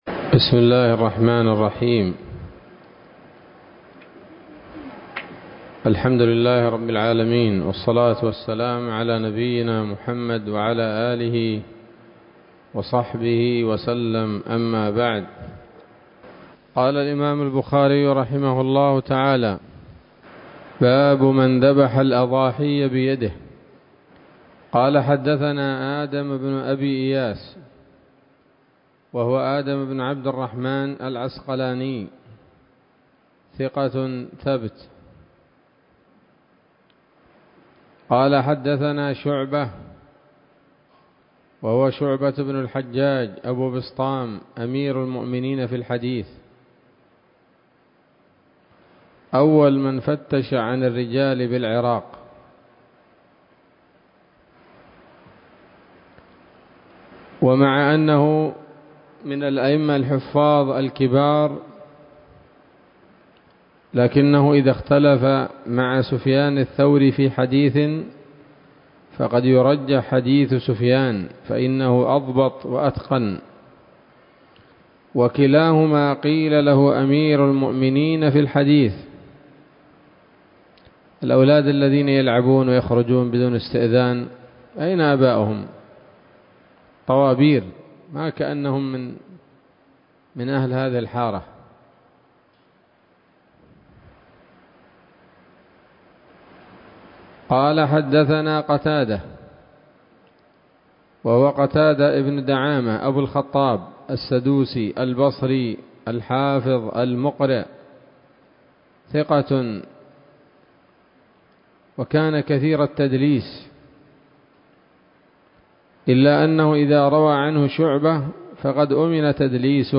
الدرس التاسع من كتاب الأضاحي من صحيح الإمام البخاري